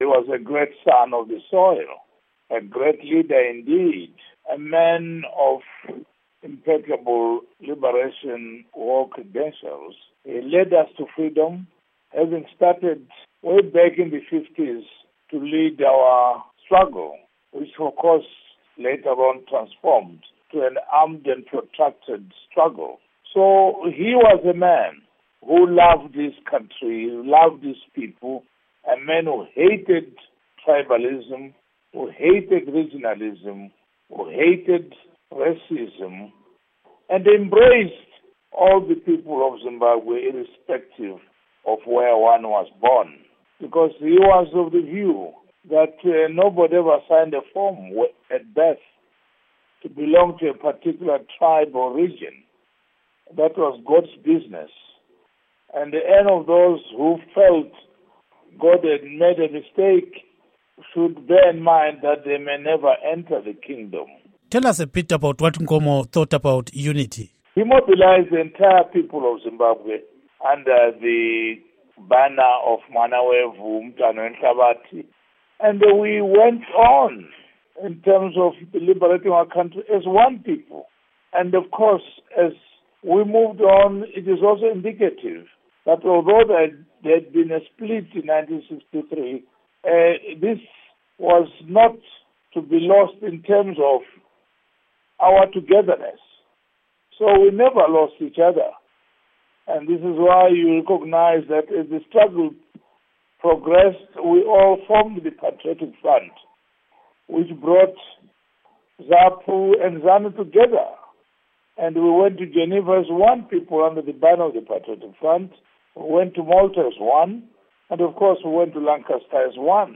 Interview With Zanu PF Chairman Simon Khaya Moyo on Joshua Nkomo's Legacy